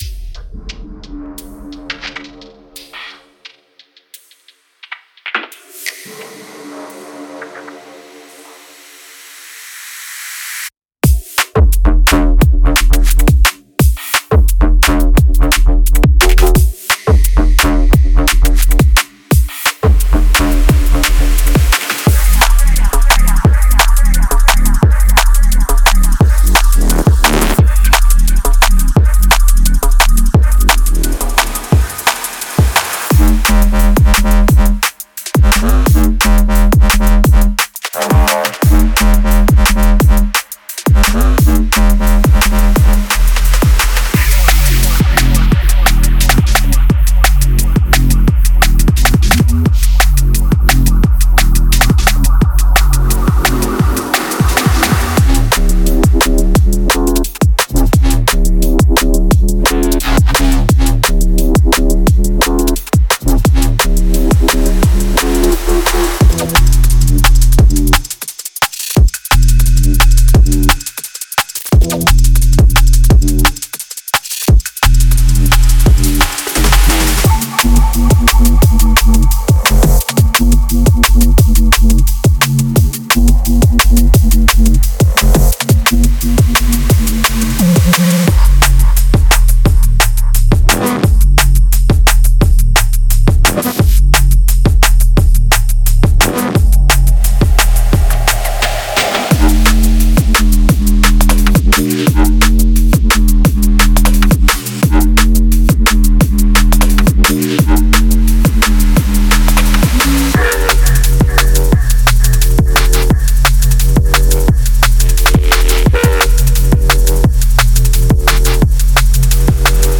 デモサウンドはコチラ↓
Genre:Drum and Bass